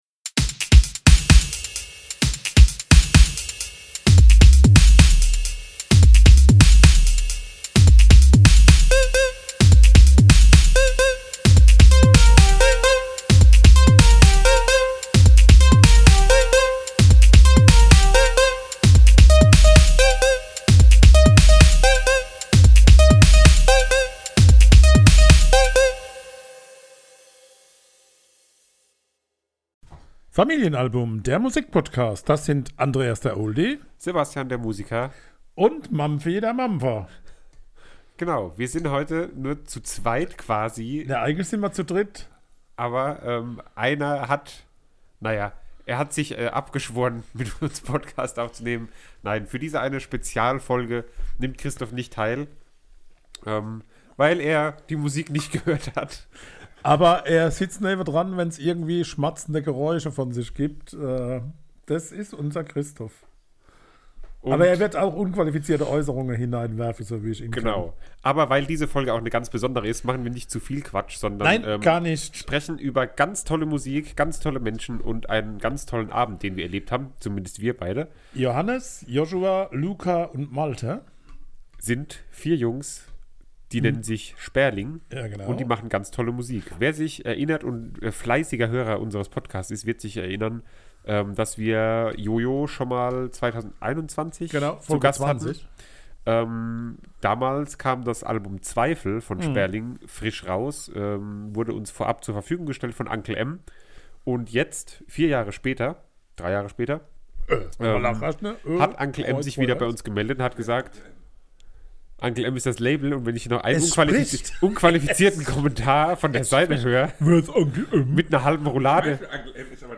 Letzte Episode Sperling – Menschen wie mir verzeiht man die Welt oder hasst sie / Konzertbericht 7. März 2024 Nächste Episode download Beschreibung Kapitel Teilen Abonnieren Wir durften zu Sperling in die Batschkapp in Frankfurt!
Albumbesprechung